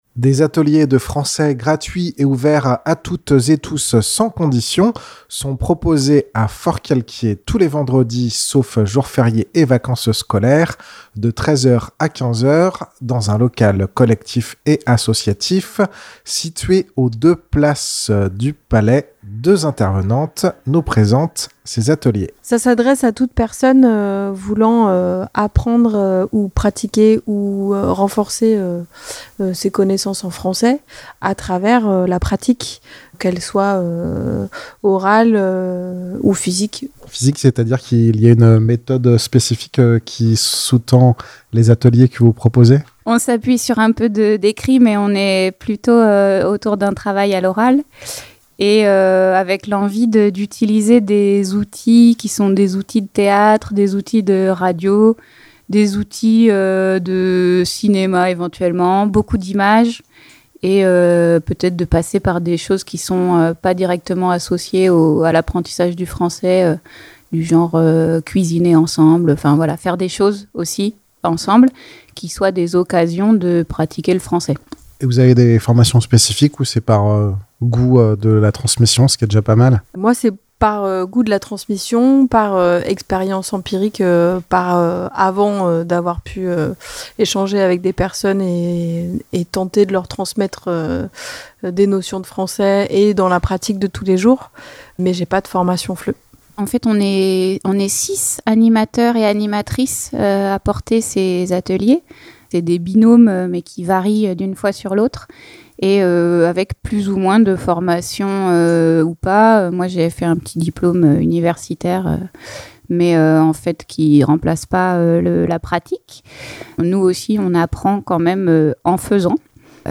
Deux intervenantes nous présentent ces ateliers qu'elles animent.